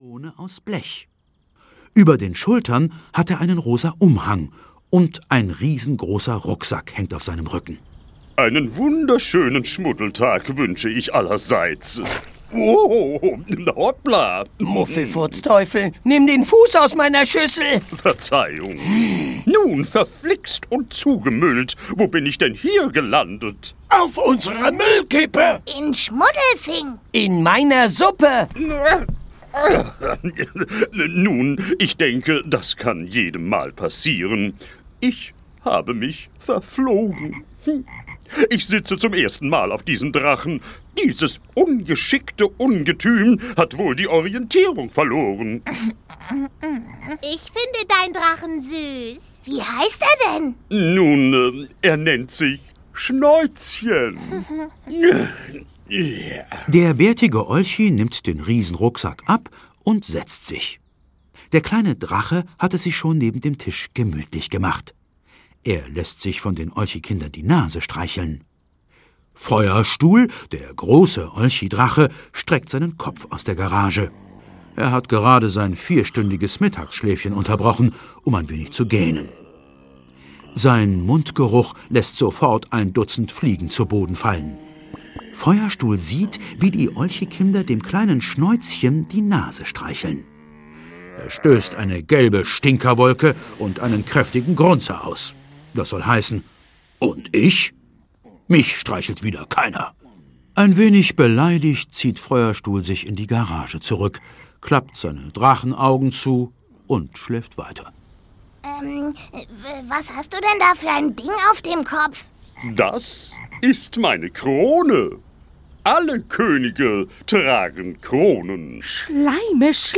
Die Olchis und der faule König - Erhard Dietl - Hörbuch